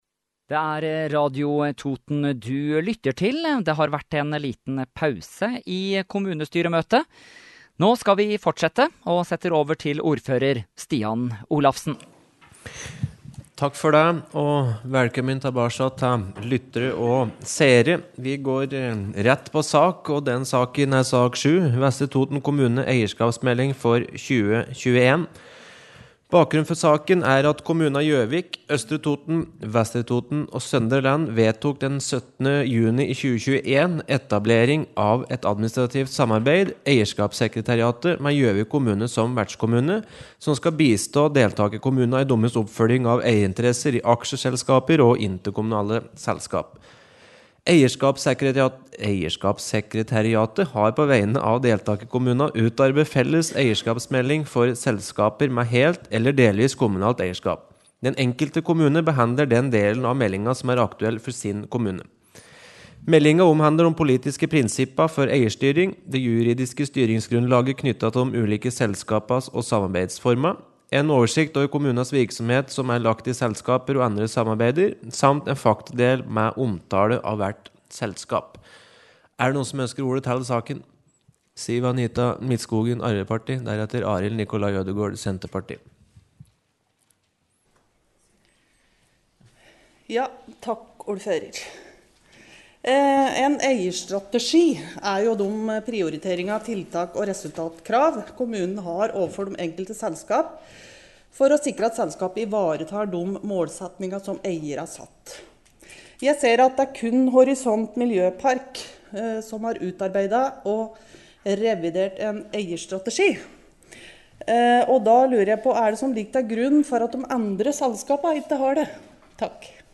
Kommunestyremøte fra Vestre Toten 2. februar – Lydfiler lagt ut | Radio Toten